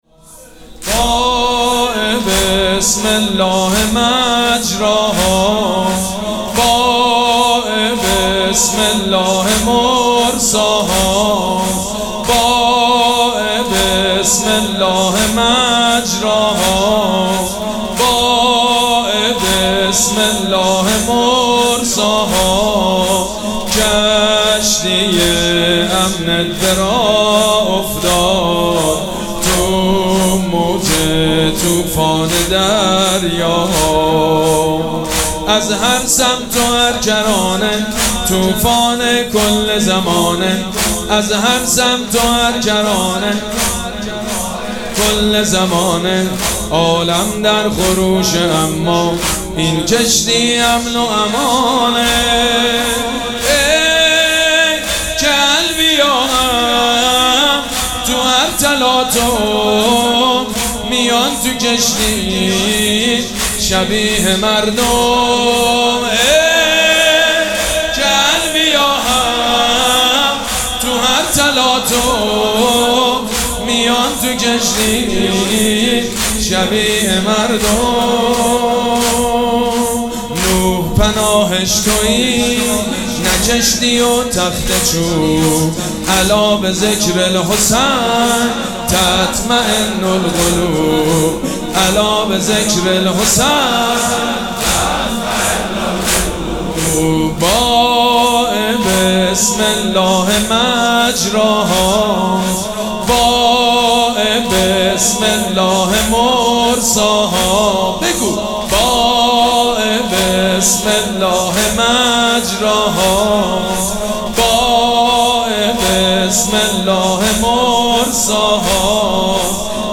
مراسم عزاداری شب پنجم محرم الحرام ۱۴۴۷
مداح